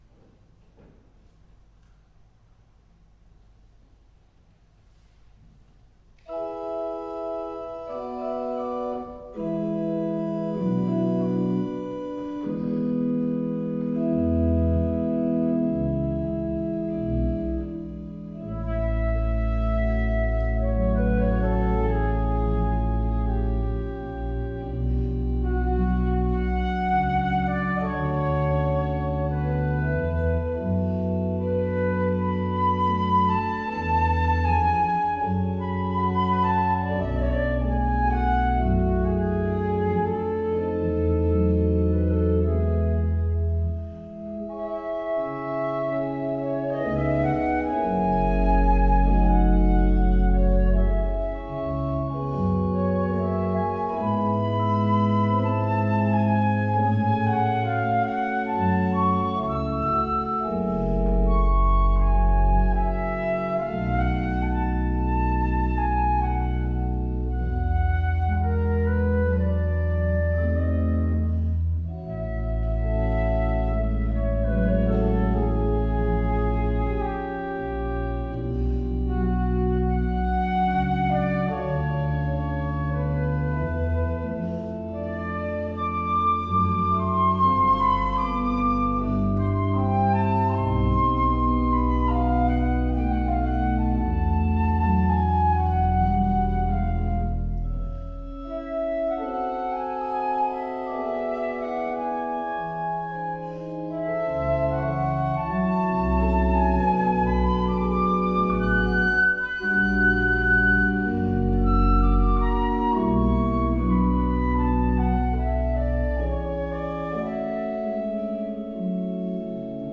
Ålands orgelfestival 2016 - inspelningar
flöjt
orgel